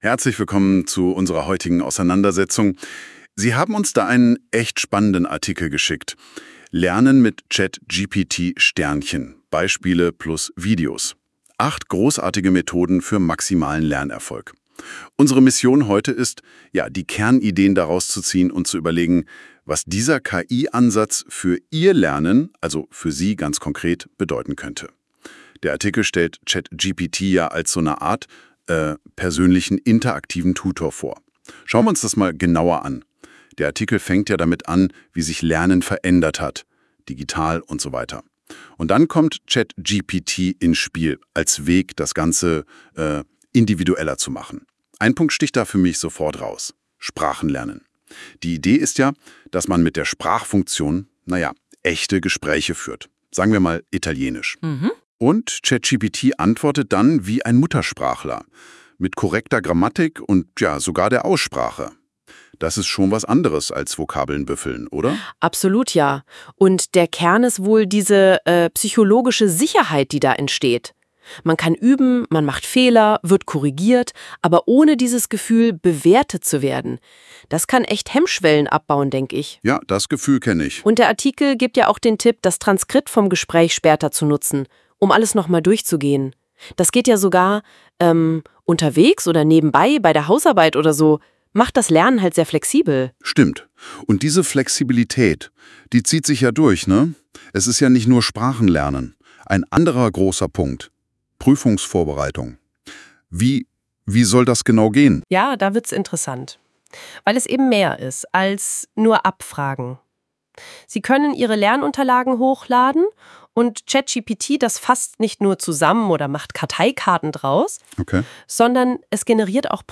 Die KI generiert dann ein Gespräch zwischen zwei menschlich klingenden Stimmen, die die wichtigsten Inhalte des hochgeladenen Dokuments diskutieren, und zwar nicht als monotone Vorlesung, sondern im Stil eines echten Podcasts bzw. einer Unterhaltung.
Als praktisches Beispiel habe ich den vorliegenden Artikel, den Sie gerade lesen, als Podcast generieren lassen.